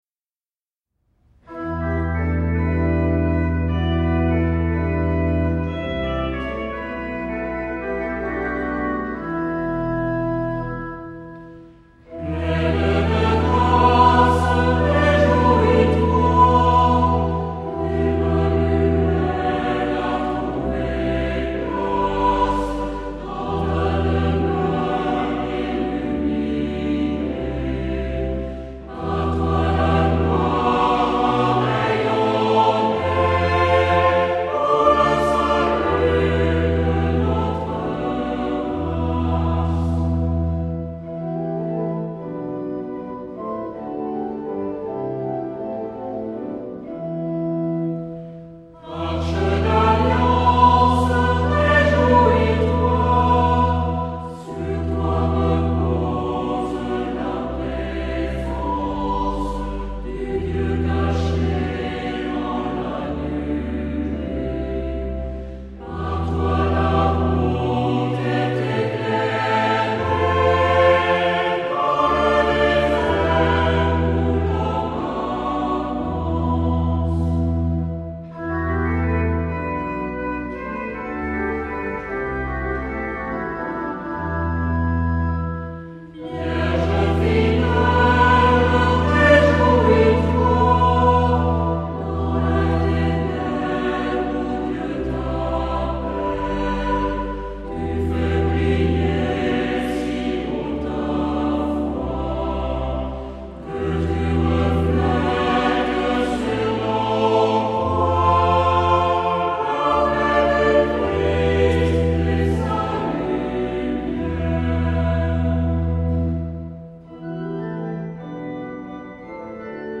Genre-Style-Form: Hymn (sacred)
Mood of the piece: adoring ; collected ; calm ; slow
Type of Choir: SATB  (4 mixed voices )
Instrumentation: Organ  (1 instrumental part(s))
Tonality: E minor